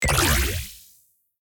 feat(audio): add AudioManager with ambiance and SFX system
succesful_repair.ogg